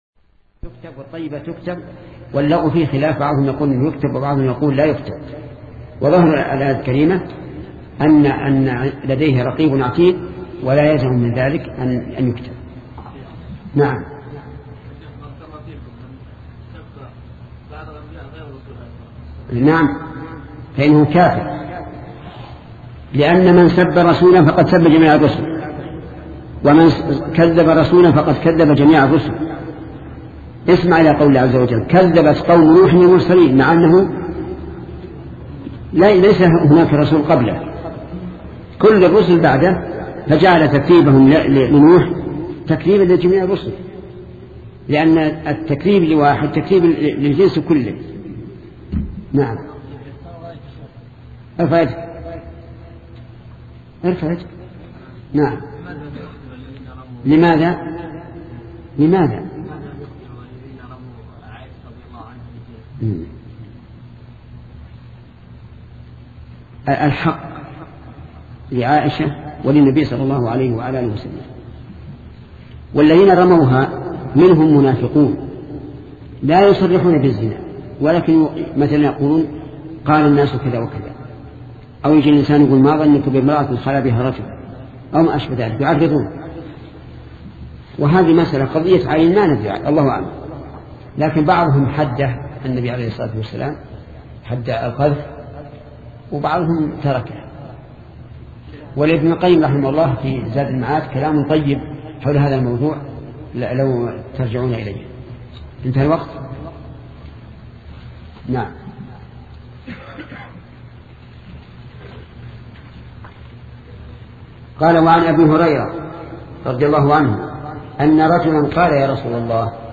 سلسلة مجموعة محاضرات شرح الأربعين النووية لشيخ محمد بن صالح العثيمين رحمة الله تعالى